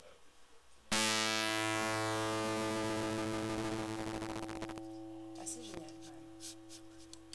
Ecouter la première note la 4 jouée par les différents instruments.
et la guitare .